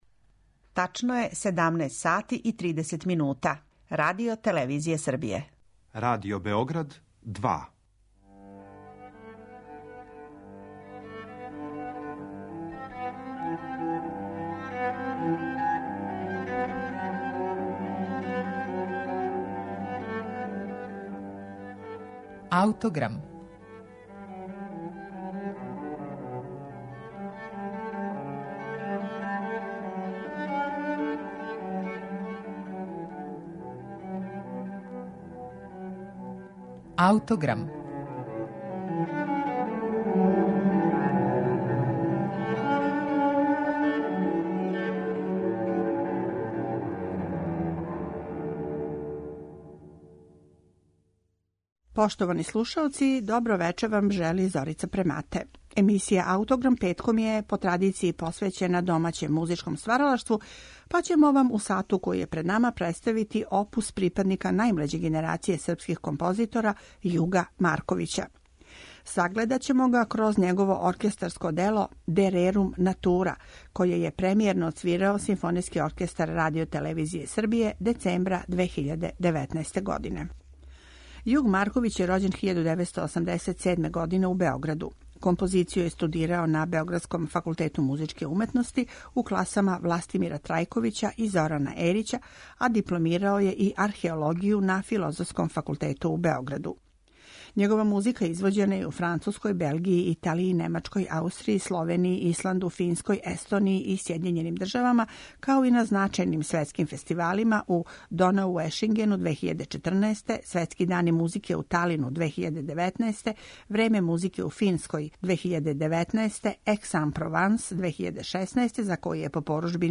за симфонијски оркестар.